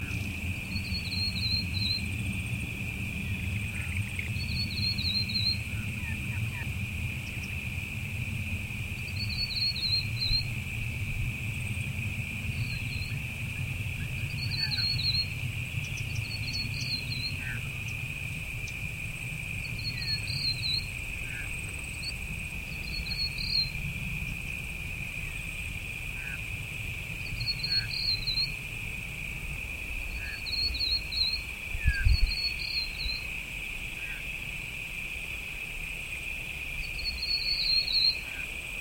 Scarlet Robins calling
To hear the robin’s distinctive call, click on the arrow on the icon below.